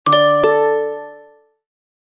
notice_sound.mp3